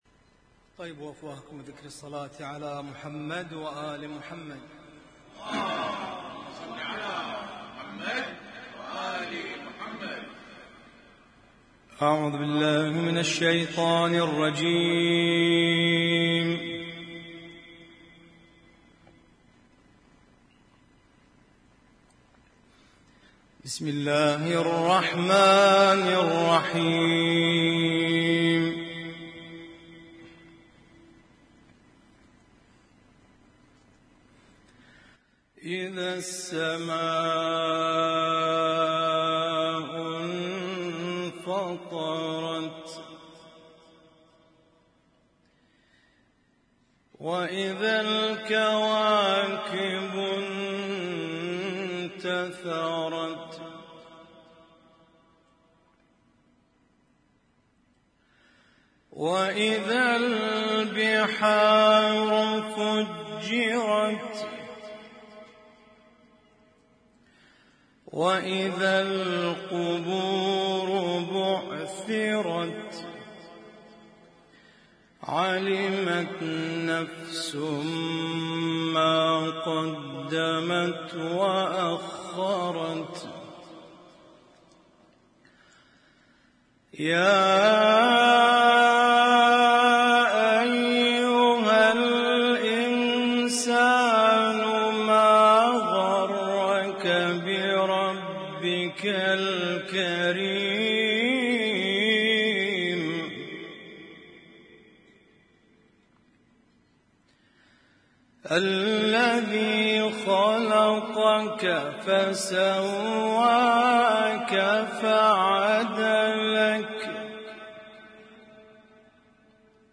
Husainyt Alnoor Rumaithiya Kuwait
اسم التصنيف: المـكتبة الصــوتيه >> القرآن الكريم >> القرآن الكريم - القراءات المتنوعة